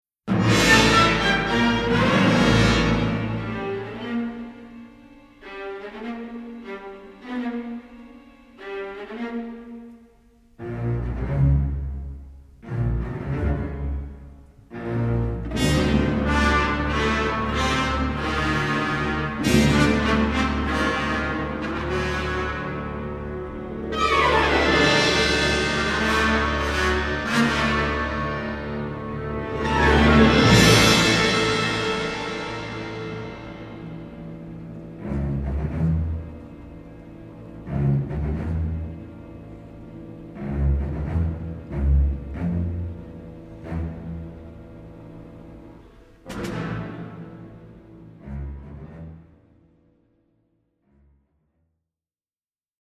unabashedly romantic
suspenseful and brooding, pastoral and uplifting